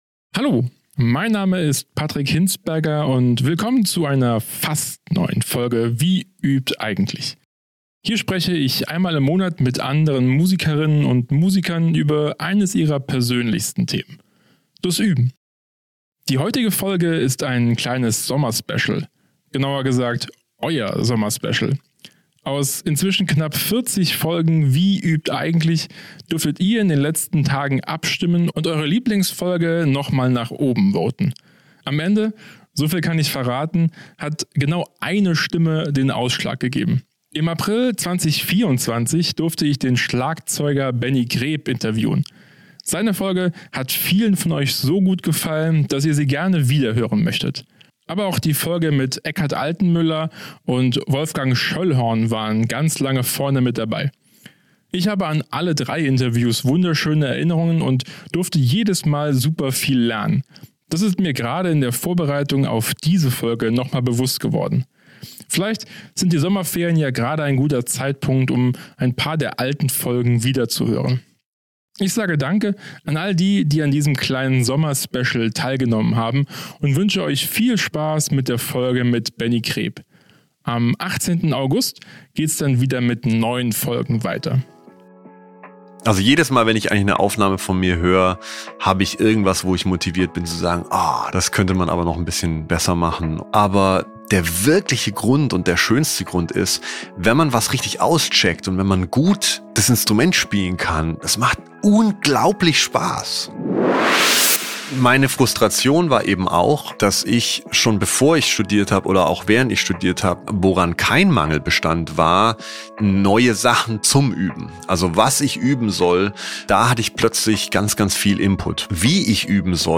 Im April 2024 durfte ich Schlagzeug Benny Greb interviewen.